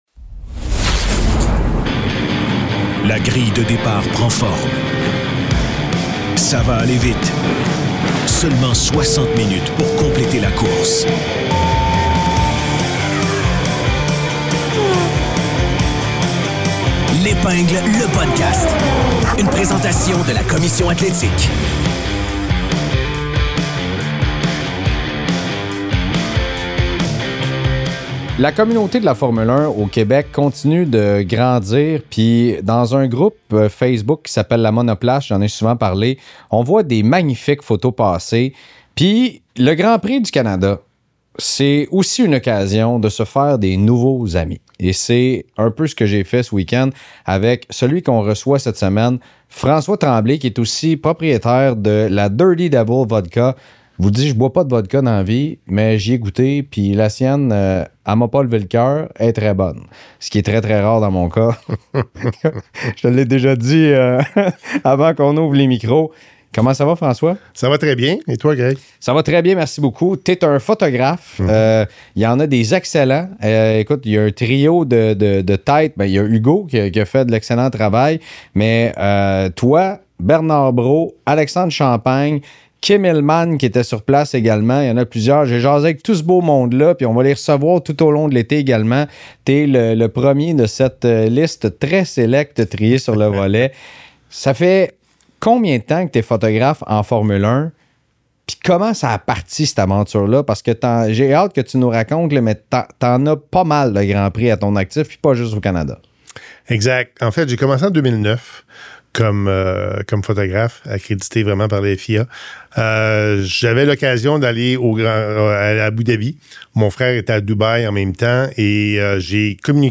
Entrevue exclusive